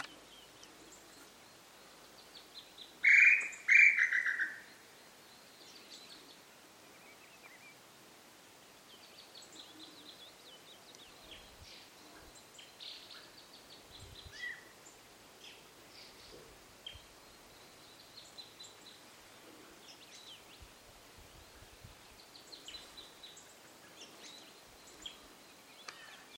Tataupá Común (Crypturellus tataupa)
Nombre en inglés: Tataupa Tinamou
Fase de la vida: Adulto
Localidad o área protegida: Reserva Natural Estricta Quebrada de las Higueritas
Condición: Silvestre
Certeza: Vocalización Grabada